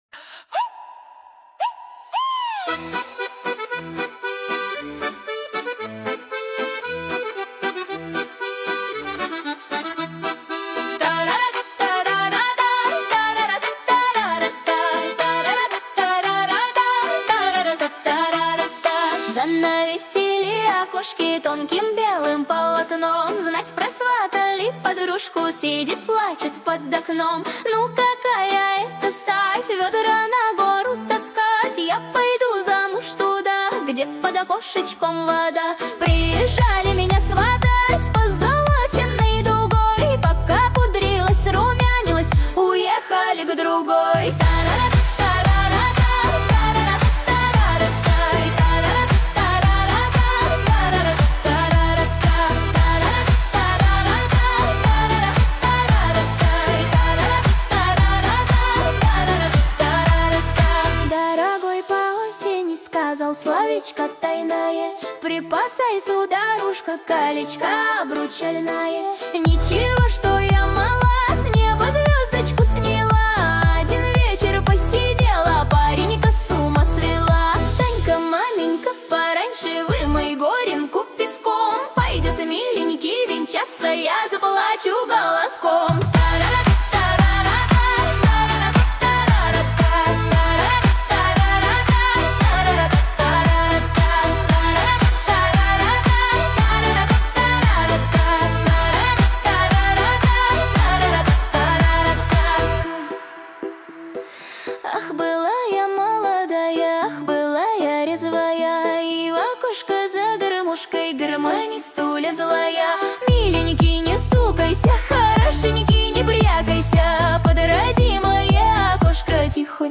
13 декабрь 2025 Русская AI музыка 66 прослушиваний